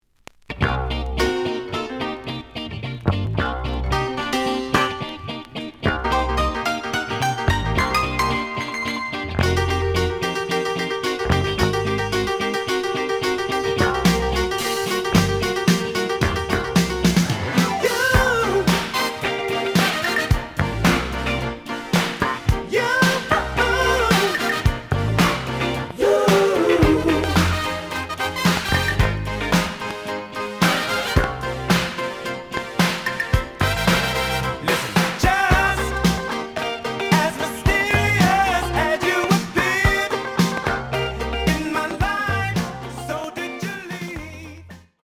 The audio sample is recorded from the actual item.
●Genre: Disco
Some click noise on first half of B side.